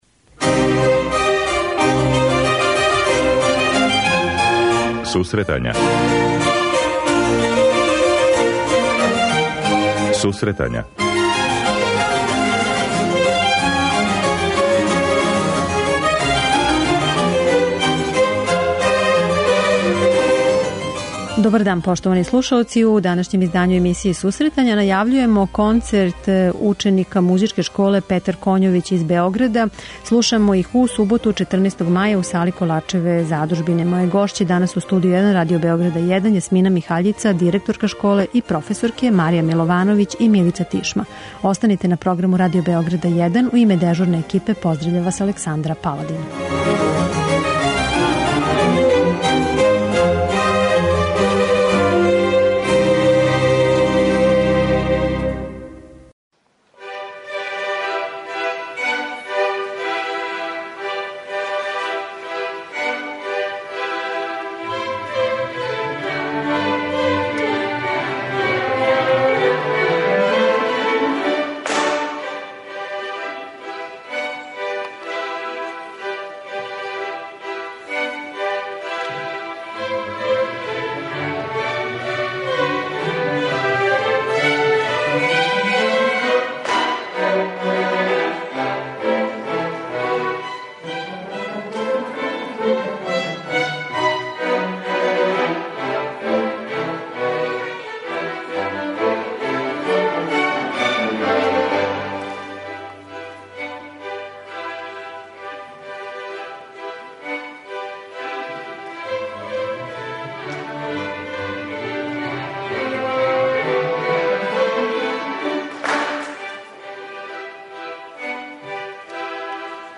У суботу, 14. маја, у Великој дворани Коларчеве задужбине концерт ће одржати најбољи ученици и ансамбли Музичке школе "Петар Коњовић" из Београда. О школи, концерту и обавезама данас разговарамо